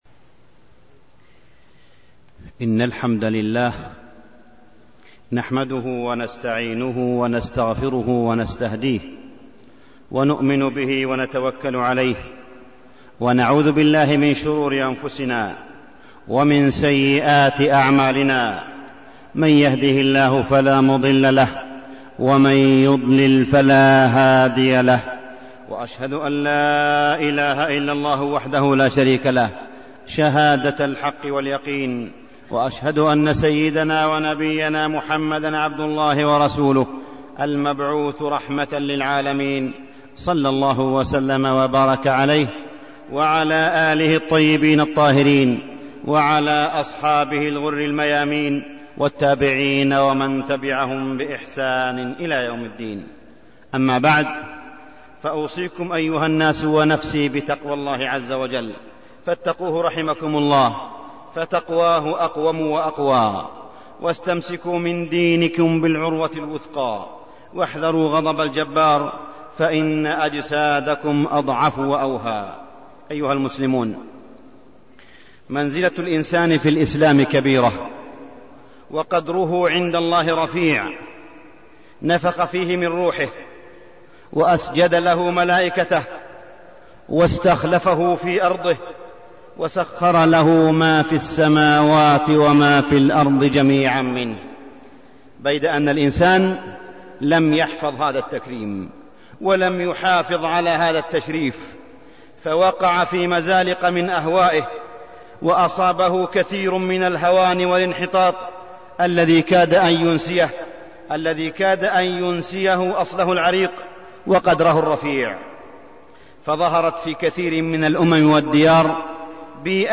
تاريخ النشر ٨ صفر ١٤١٨ هـ المكان: المسجد الحرام الشيخ: معالي الشيخ أ.د. صالح بن عبدالله بن حميد معالي الشيخ أ.د. صالح بن عبدالله بن حميد مؤذن الرسول بلال رضي الله عنه